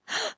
Gasp 1.wav